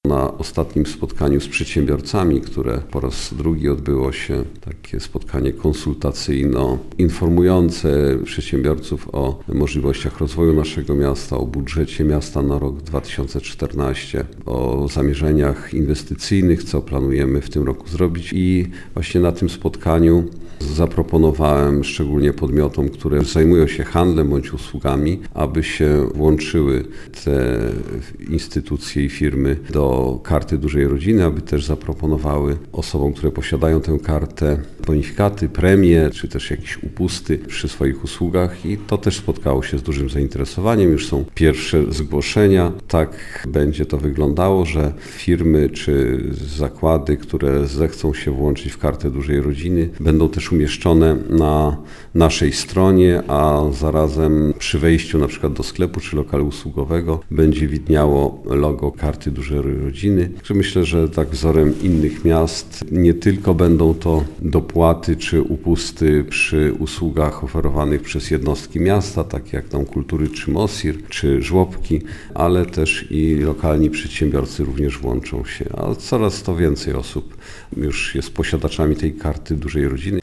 System wspierania dużych rodzin rozwija się. Zainteresowani przystąpieniem do programu ulg i rabatów dla rodzin wielodzietnych są niektórzy przedsiębiorcy z Krasnegostawu - mówi burmistrz Andrzej Jakubiec.